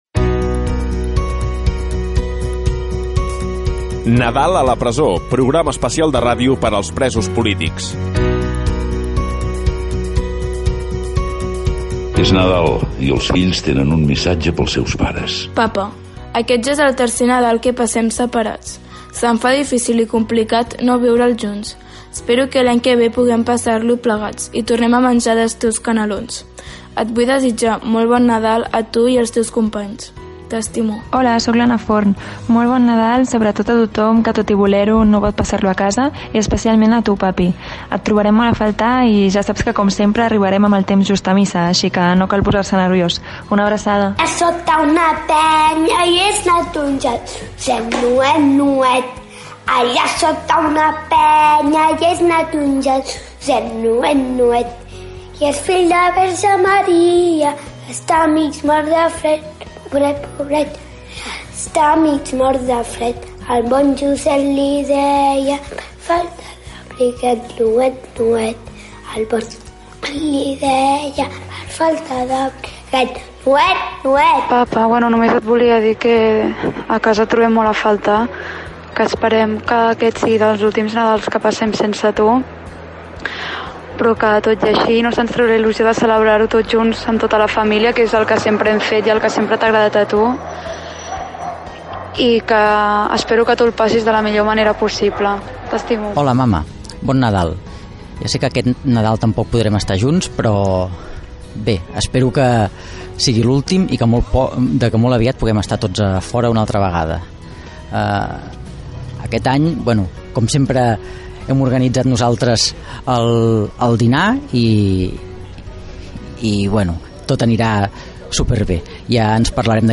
Careta del programa, desitjos de bon Nadal de fills i familiars dels presos polítics, noms dels presos polítics, "Nadala contra la repressió"
FM